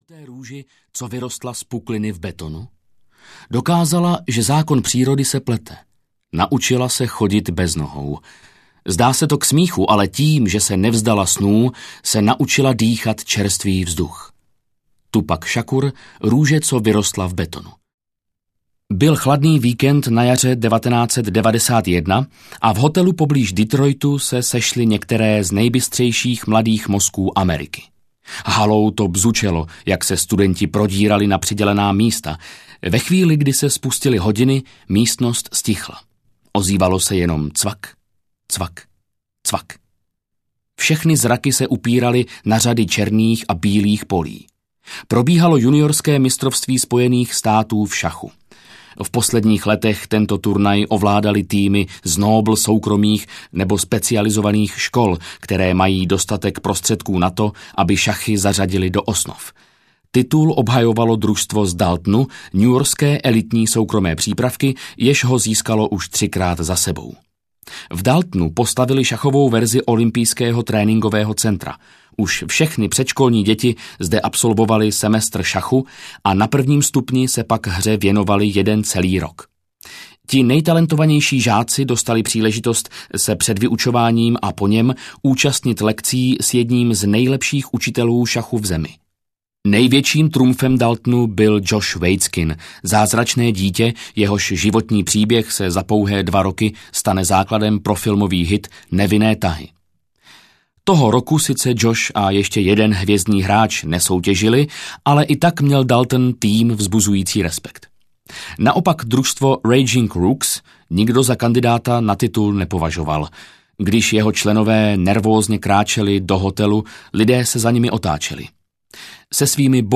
Skrytý potenciál audiokniha
Ukázka z knihy